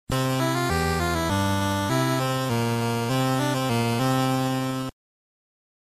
RICH FOLK DOORBELL 2